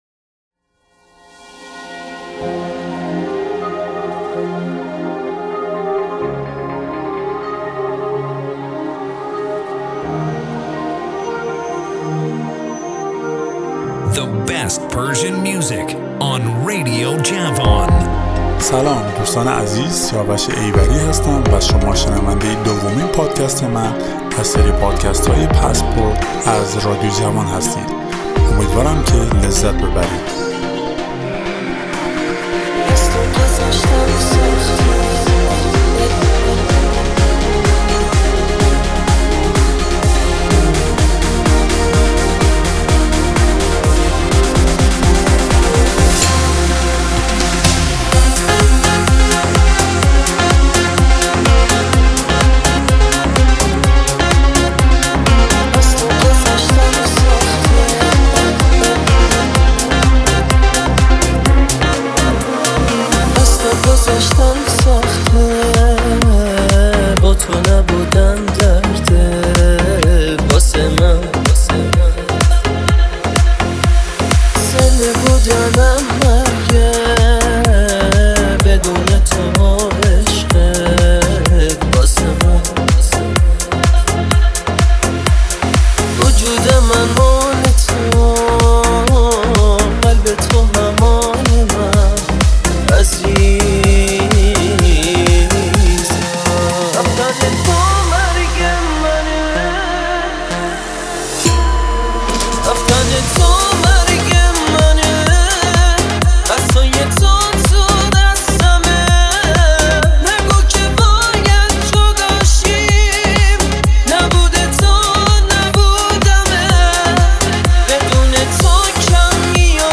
ریمیکس
Music Podcast